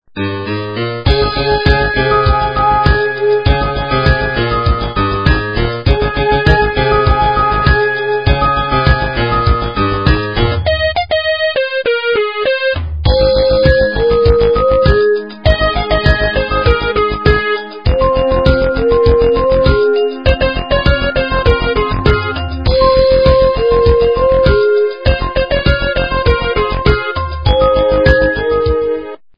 Polyphonic Ringtones